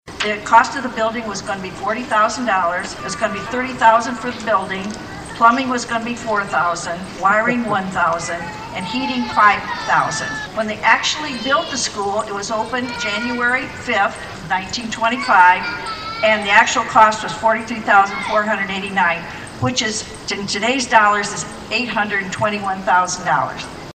(STURGIS) – A special celebration was held to honor a Sturgis School building.
shared with the audience some of the background on when the building was constructed and the cost.